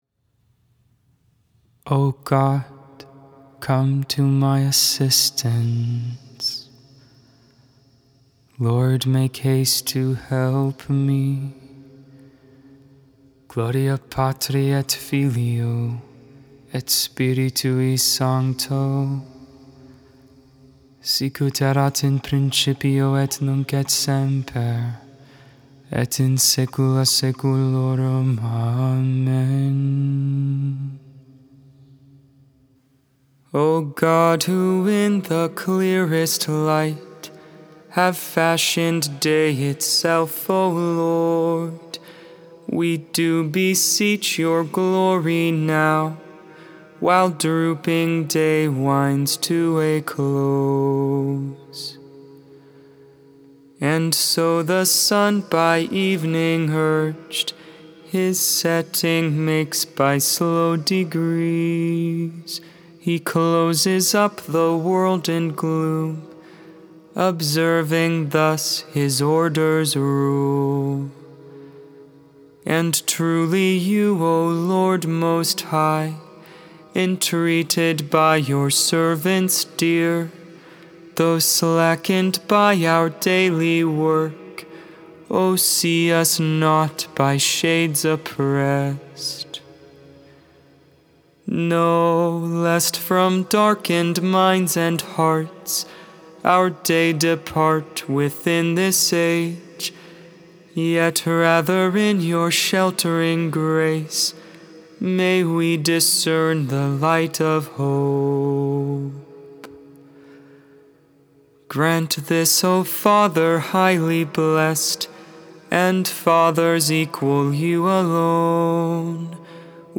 (tone 4)